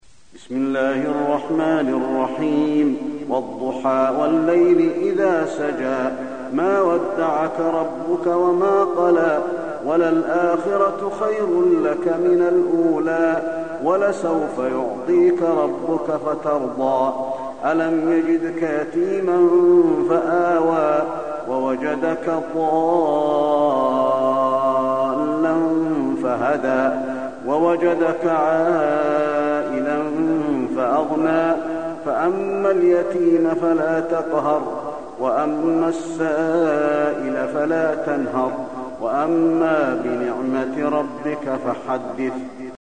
المكان: المسجد النبوي الضحى